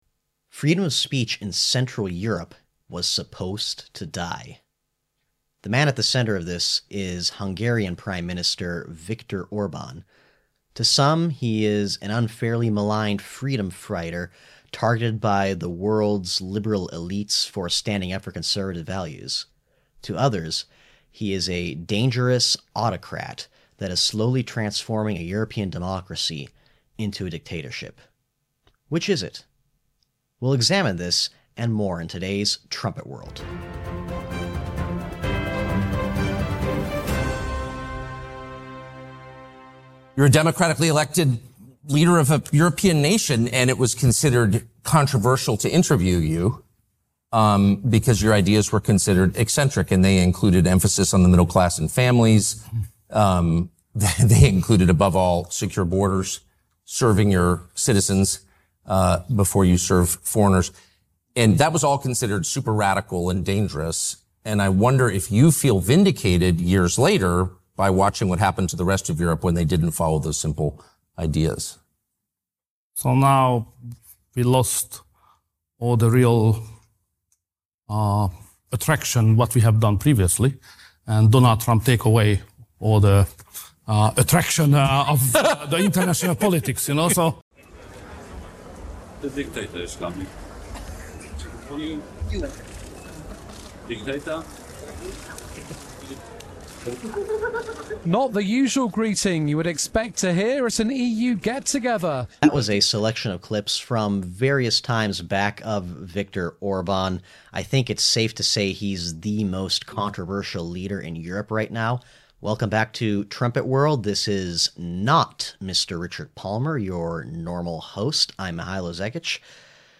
In an exclusive interview